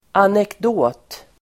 Uttal: [anekd'å:t]